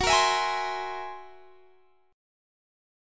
F#9b5 Chord
Listen to F#9b5 strummed